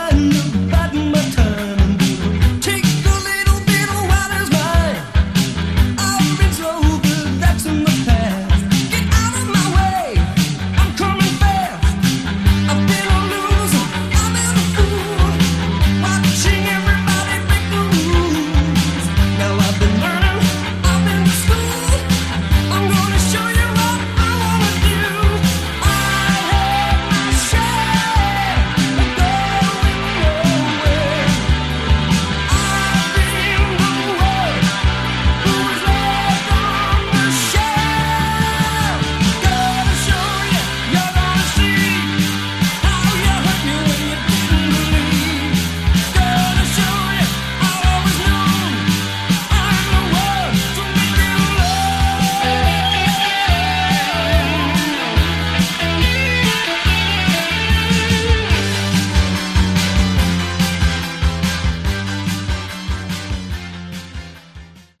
Category: Melodic Rock
vocals
guitar
bass
Keys
drums